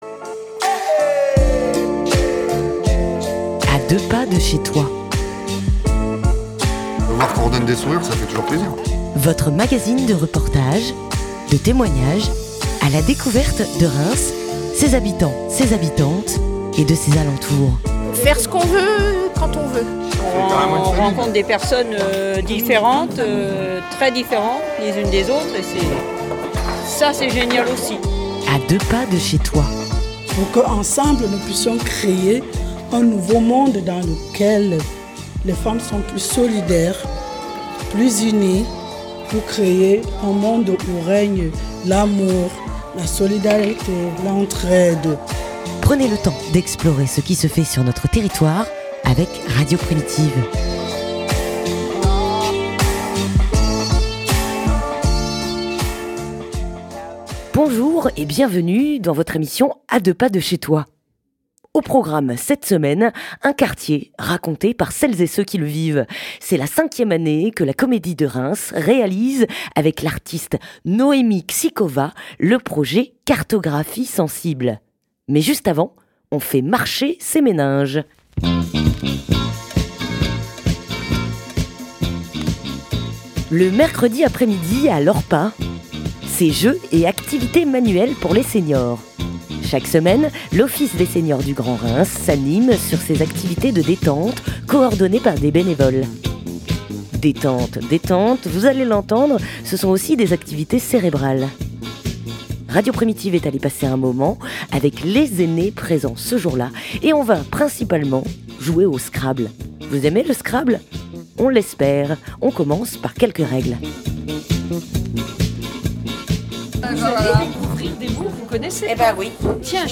On joue au scrabble à l'ORRPA avec trois dames retraitées. Chaque mercredi après-midi à l'Office des Séniors du Grand Reims, c'est jeux et activités manuelles.